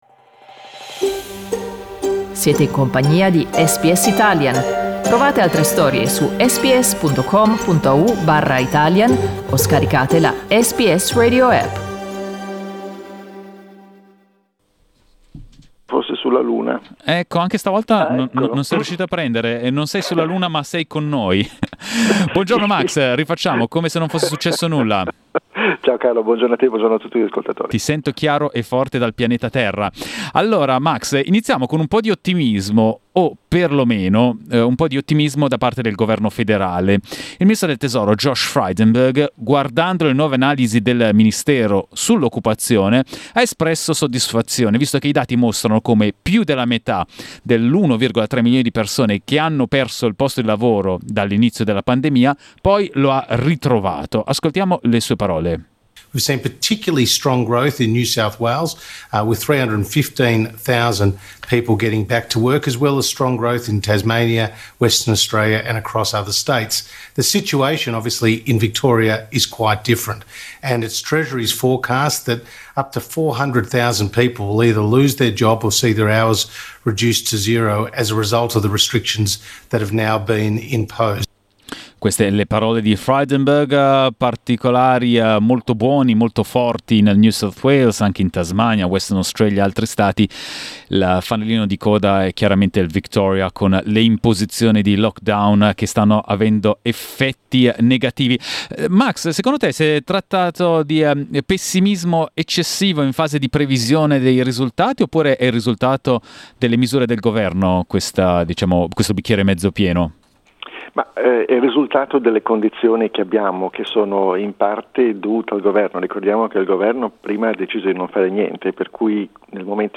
Ascoltate l’analisi del professore di finanza